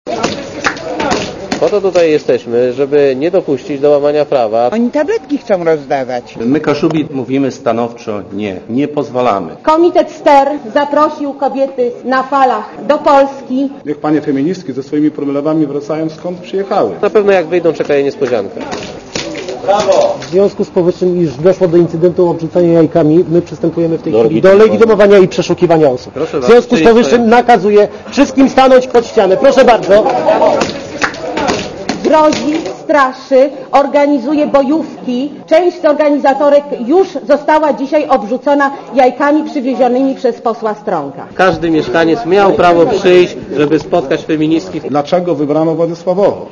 Posłuchaj protestujących (180 KB)